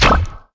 launch_shield_impact1.wav